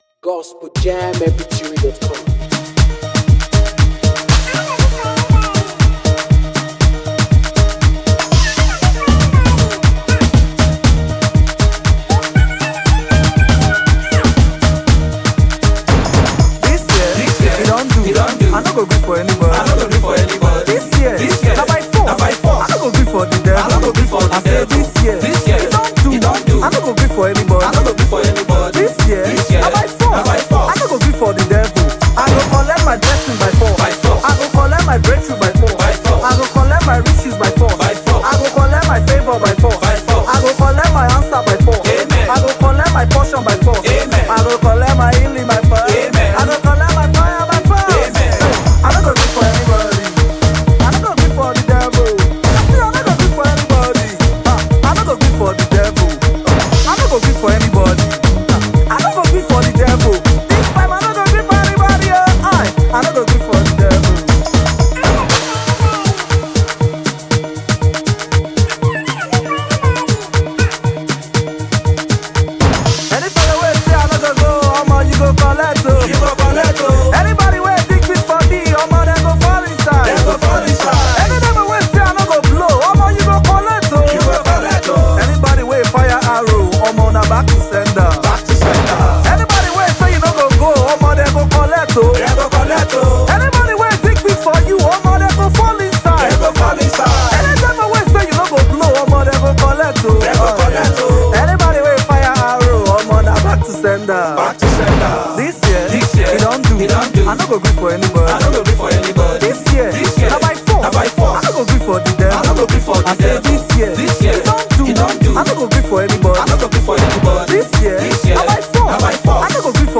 NUMBER 1 AFRICA GOSPEL PROMOTING MEDIA